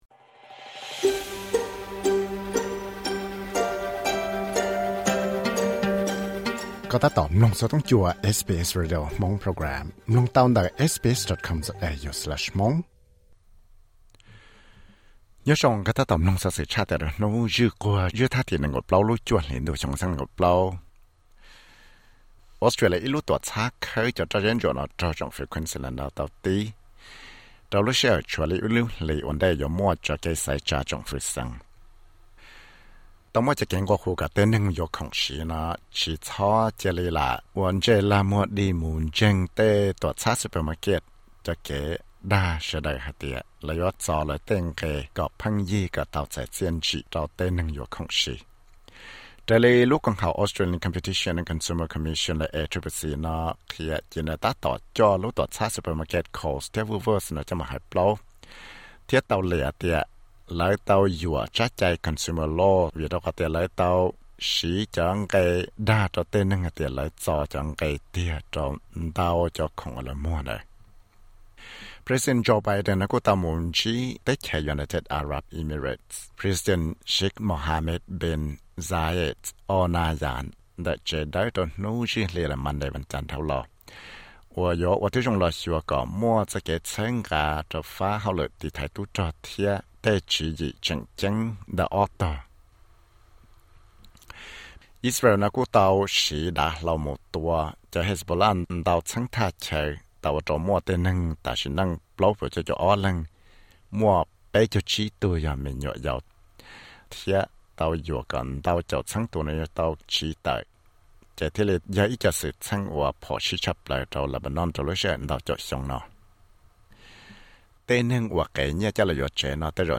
Xov xwm luv